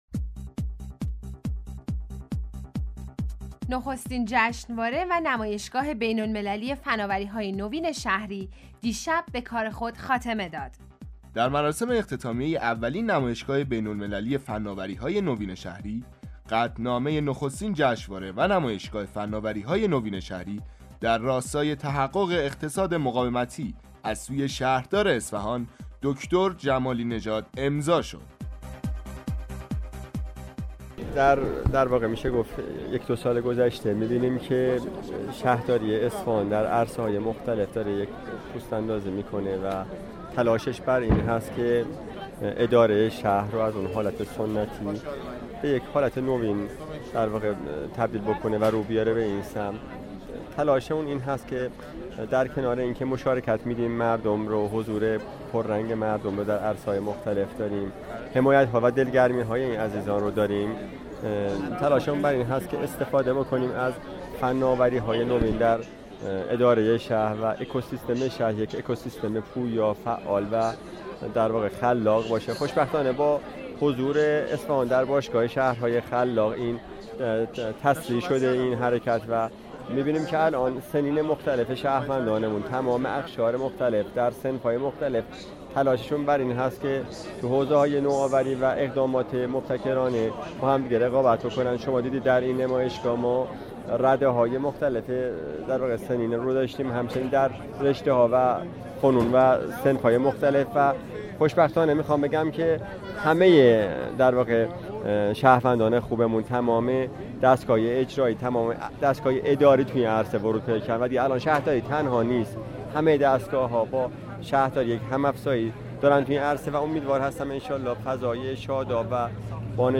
اختتامیه اولین نمایشگاه بین المللی فناوری های نوین شهری
نمایشگاه بین المللی فناوری های نوین در حوزه مدیریت شهری از ۱۳ تا ۱۵ مرداد ماه سال جاری در محل دائمی نمایشگاه های بین المللی واقع در پل شهرستان برپا شد. مراسم اختتامیه نمایشگاه بین المللی فناوری های نوین با حضور مدیران شهری و شرکت های بنیان و میهمانان خارجی آغاز شد.